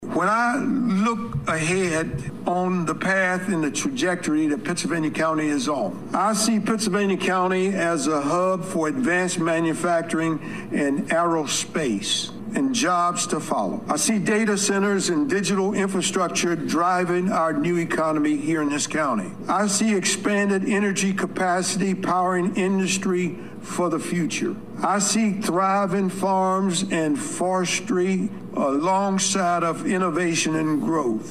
Pittsylvania County Board of Supervisors Chairman Delivers State of the County Address